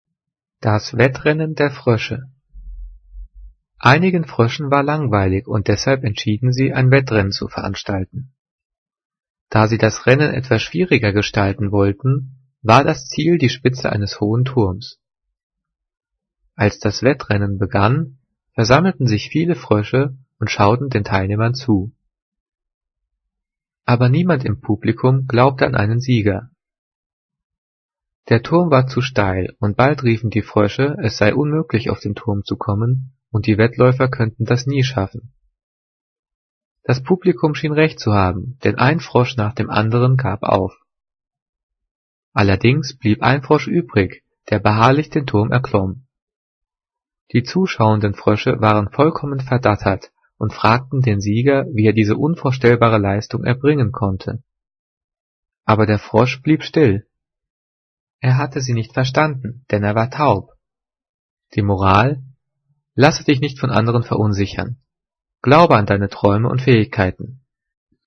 Gelesen:
gelesen-das-wettrennen-der-froesche.mp3